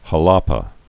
(hə-läpə, hä-läpä)